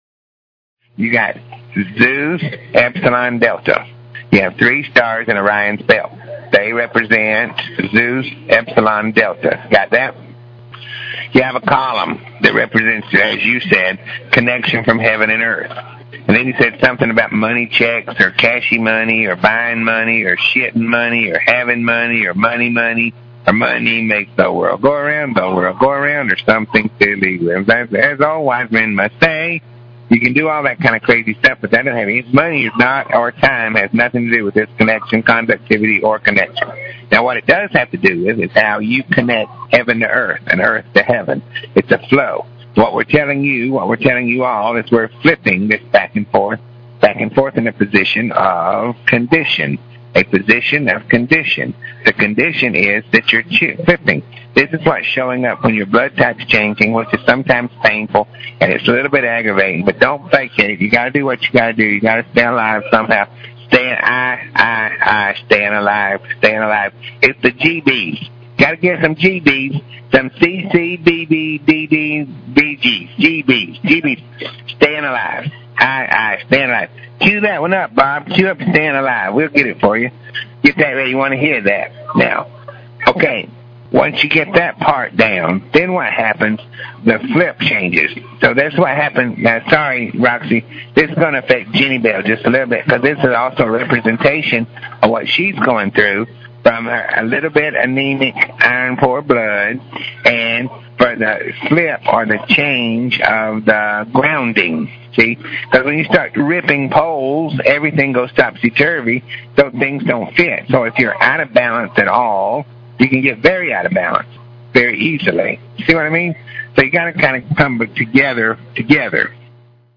semi-rant